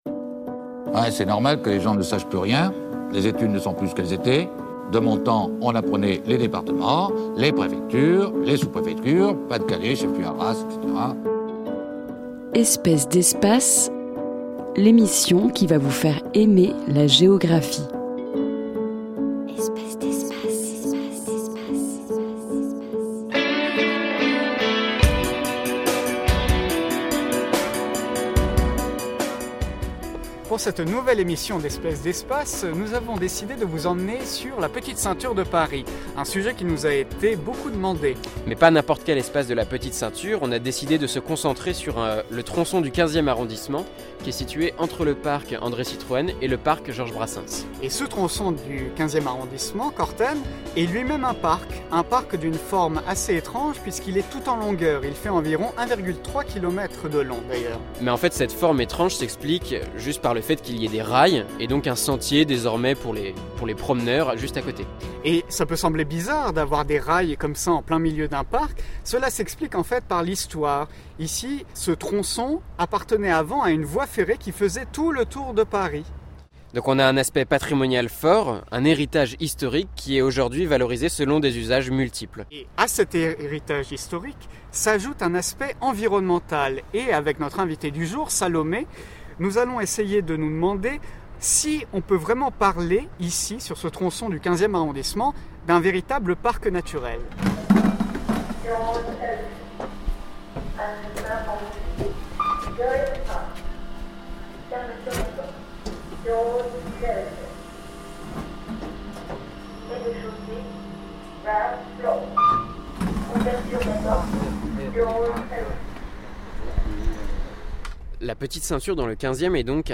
en compagnie des joggeurs qui longent les rails